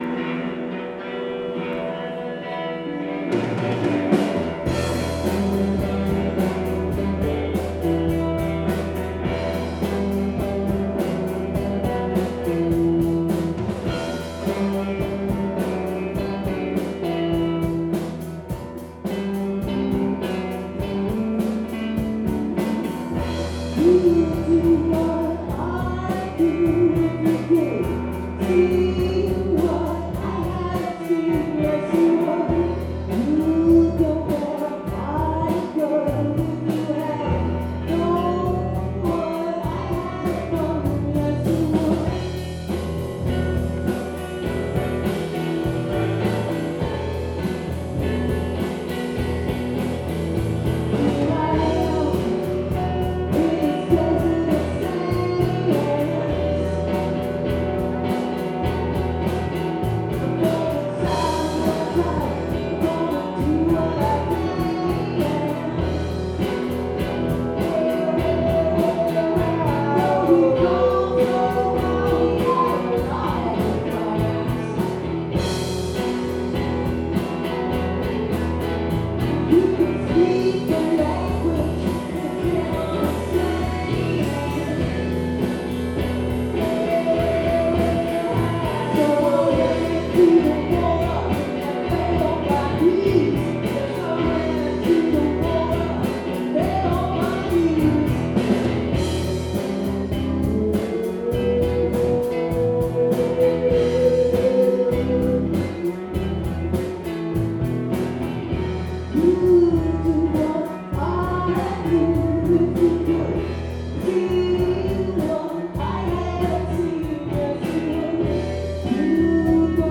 le punk rock dissonant